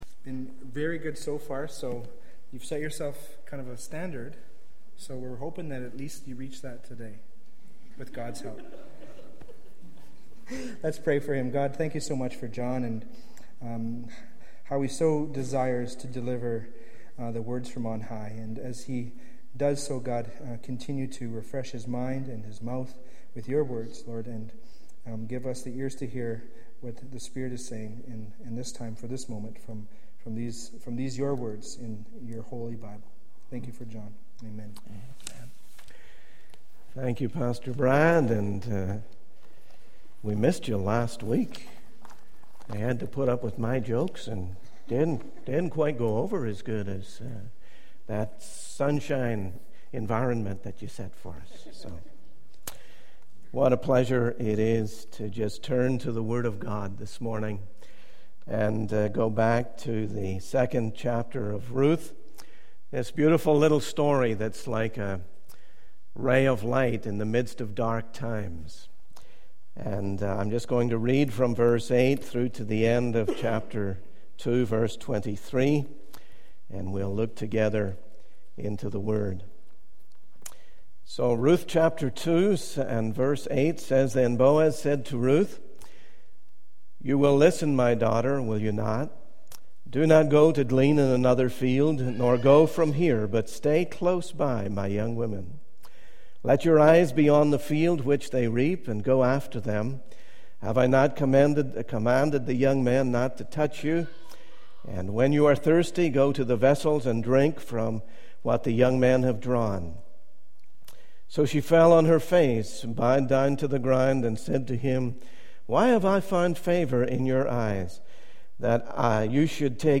The sermon concludes with a call to action, urging listeners to embrace the invitation of Jesus and seek His comfort and guidance.